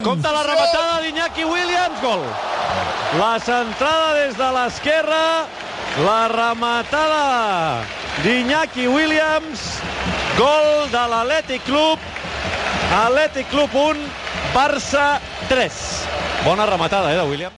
Transmissió del partit de la Copa del Rei de futbol masculí entre l'Athletic Club i el Futbol Club Barcelona.
Narració del gol d'Iñaki Williams per a l'Athletic Club.
Esportiu